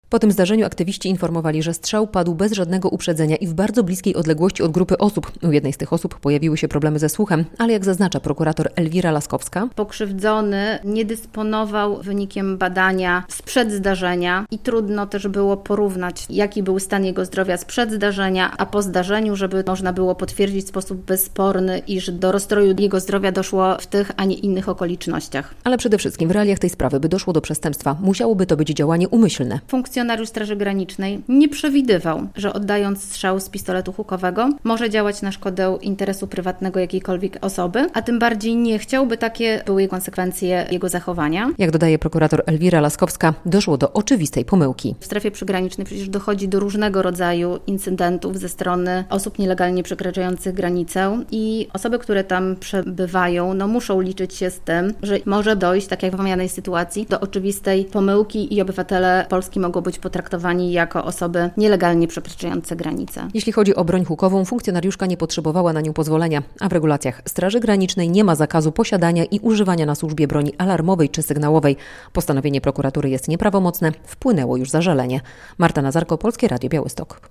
Prokuratura umorzyła śledztwo w sprawie użycia broni hukowej przez funkcjonariuszkę Straży Granicznej - relacja